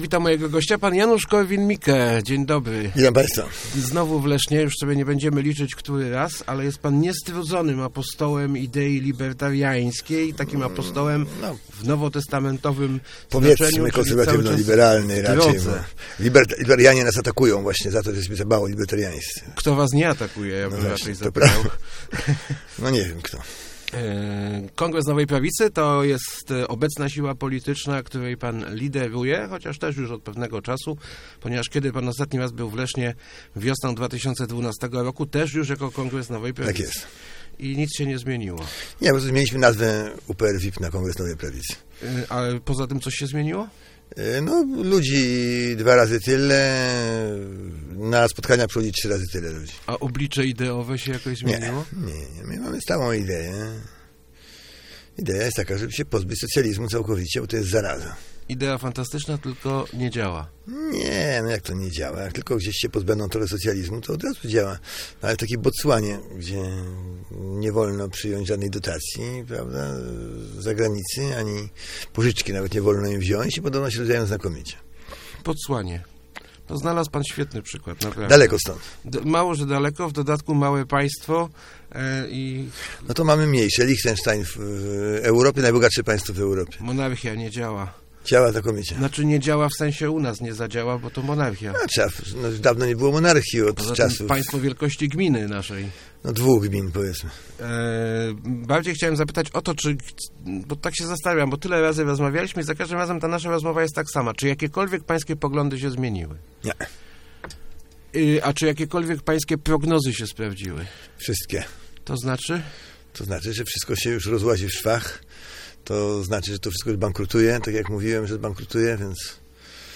Jeżeli Polska wyjdzie z Unii Europejskiej, ruszymy w górę jak rakieta - mówił w Rozmowach Elki Janusz Korwin-Mikke. Lider Kongresu Nowej Prawicy kandyduje w wyborach do Europarlamentu, jednak jak mówi robi to, żeby "zdemontować" Unię.